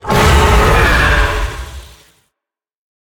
Sfx_creature_hiddencroc_alert_04.ogg